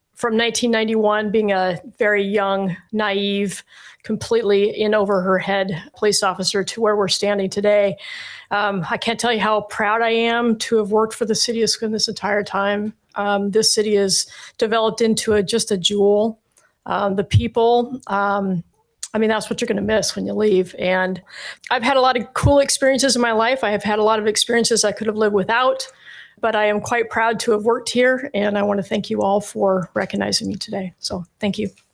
She spoke a few words at Monday’s meeting.